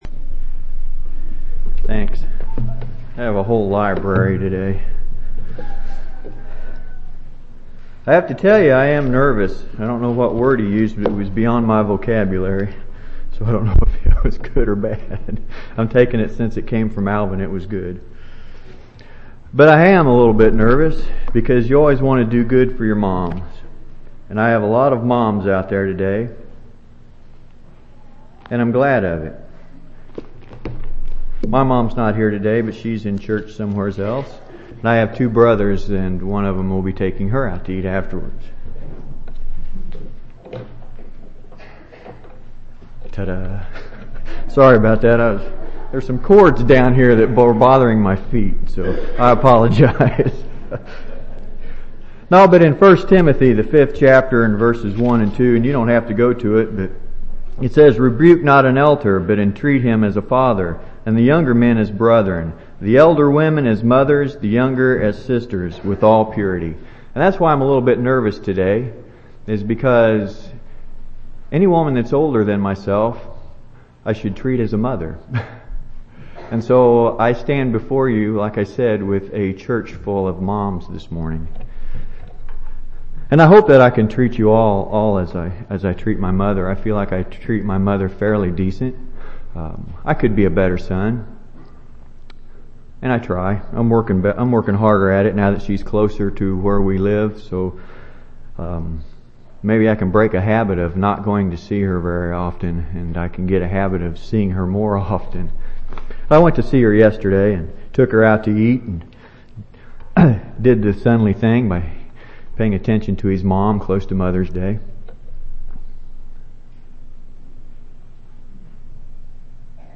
5/11/2003 Location: Temple Lot Local Event